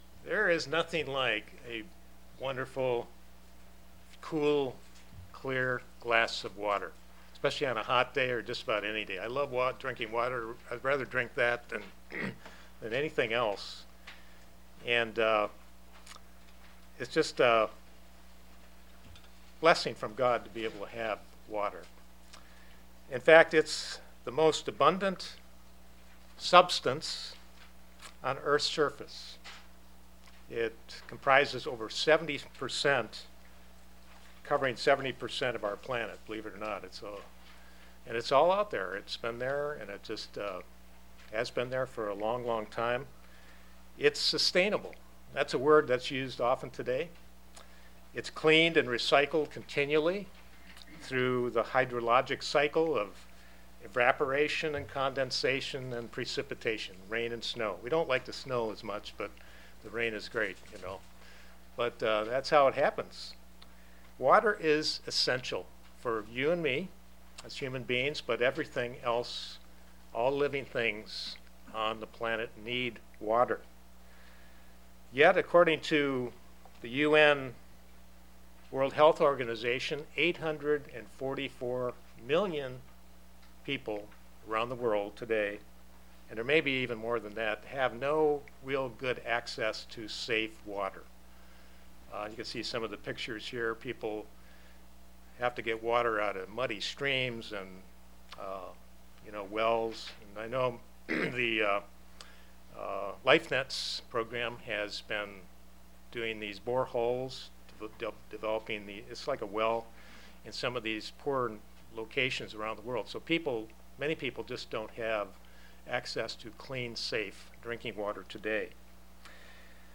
Given in Kingsport, TN Knoxville, TN London, KY